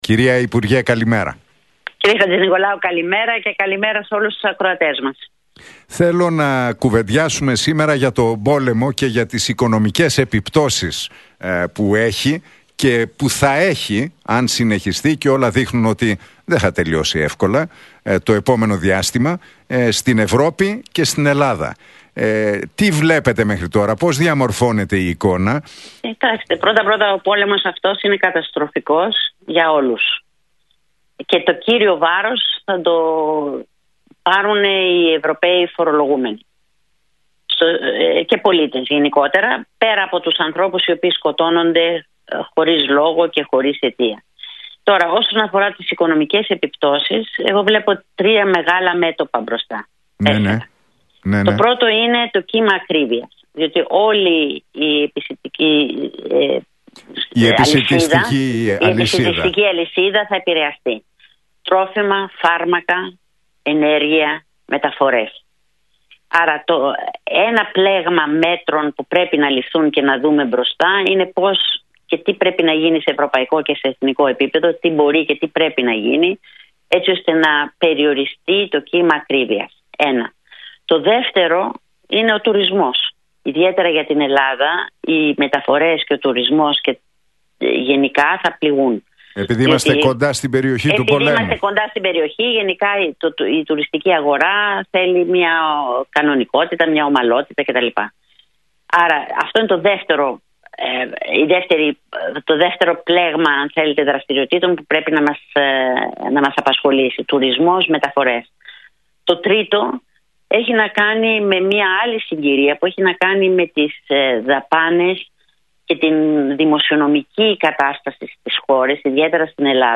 Λούκα Κατσέλη στον Realfm 97,8: Υπάρχει κίνδυνος να στεγνώσει η αγορά και να χρειαστεί να πάμε σε δανεισμό από τις αγορές